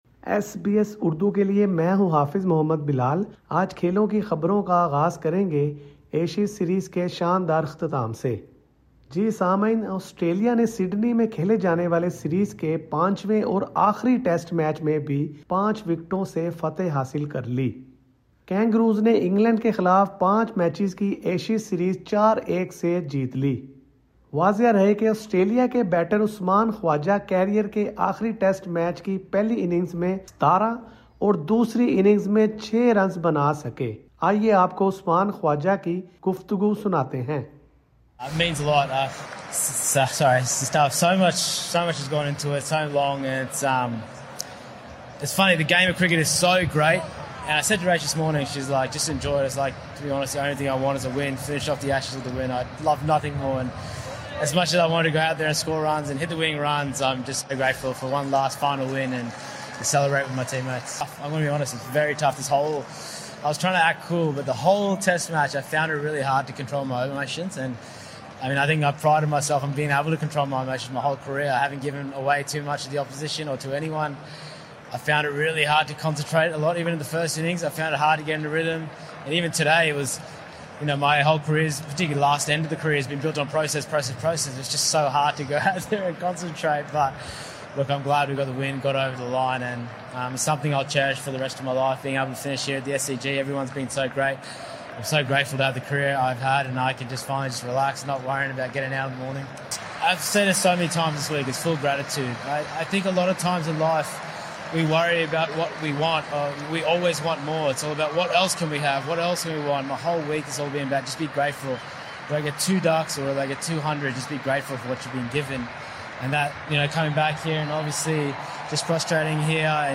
آئیے آپکو عثمان خواجہ کی گفتگو سناتے ہیں